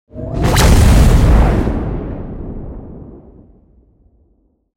جلوه های صوتی
دانلود صدای رعدو برق 14 از ساعد نیوز با لینک مستقیم و کیفیت بالا
برچسب: دانلود آهنگ های افکت صوتی طبیعت و محیط دانلود آلبوم صدای رعد و برق از افکت صوتی طبیعت و محیط